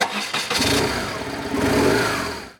Moto marca BMW arrancando
motocicleta
Sonidos: Transportes